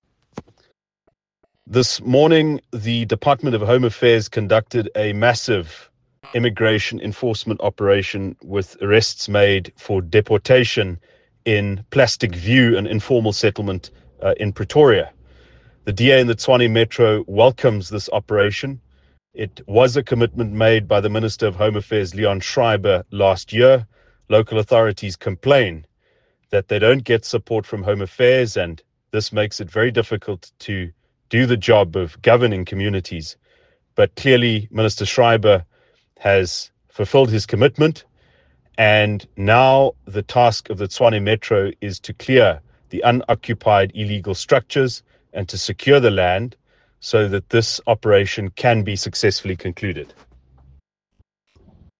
Note to Editors: Please find English and Afrikaans soundbites by Ald Cilliers Brink